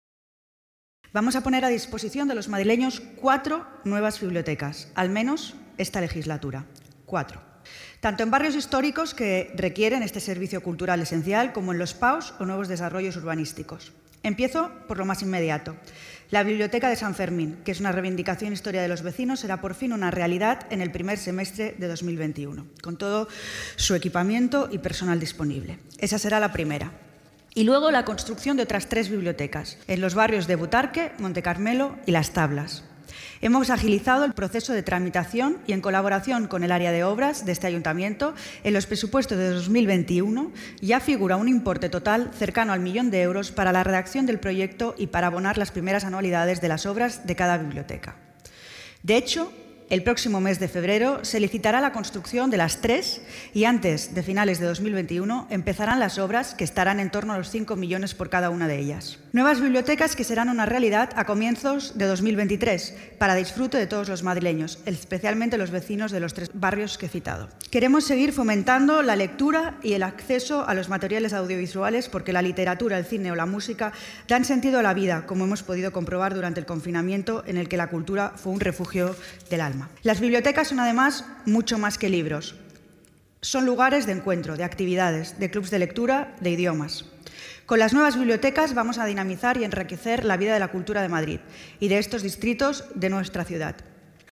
La delegada de Cultura, Turismo y Deporte, Andrea Levy, ha anunciado durante su intervención en la Comisión Permanente del área que la ciudad de Madrid contará con cuatro nuevas bibliotecas en funcionamiento antes de mediados de 2023, dando cumplimiento al programa de Gobierno.